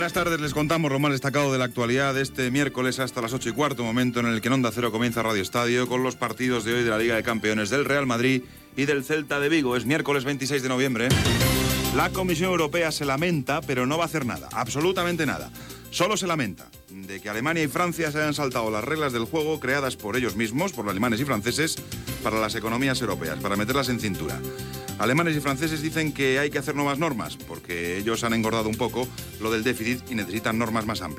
Titulars informatius en una edició curta del programa.
Informatiu